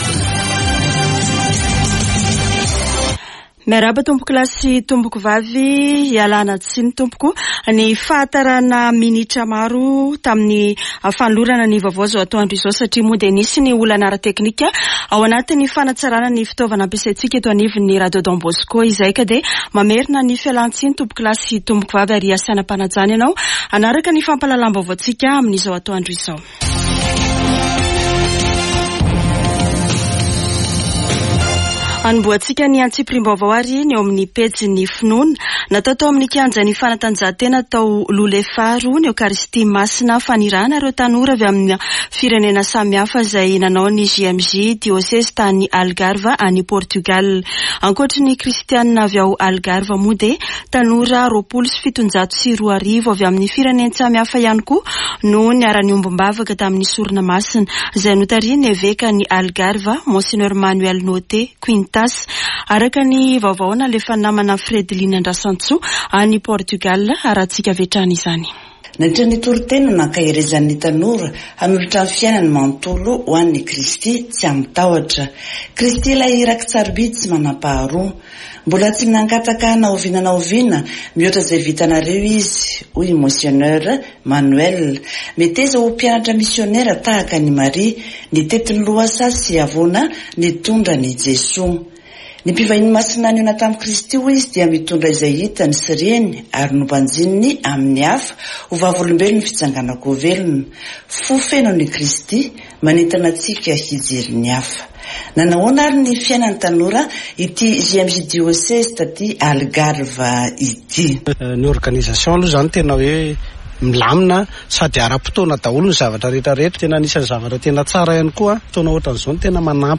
[Vaovao antoandro] Alatsinainy 31 jolay 2023